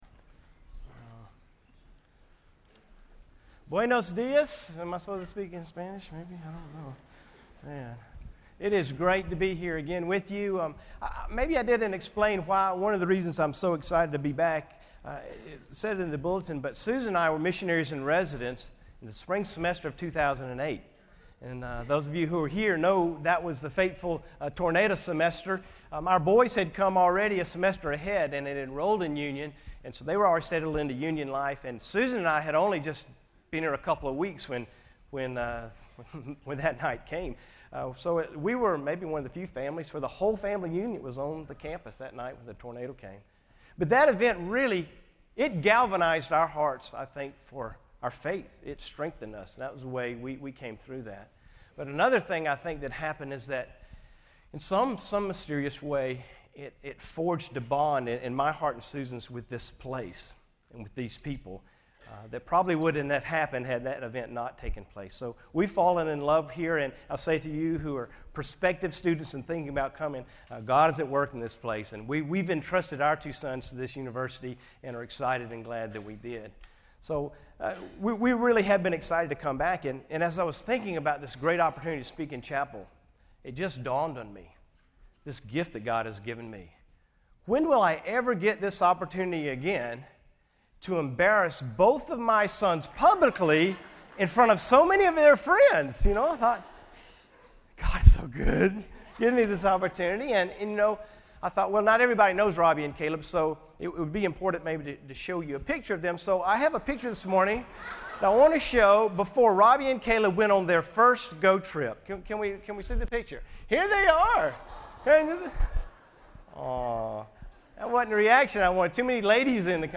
GO Week Chapel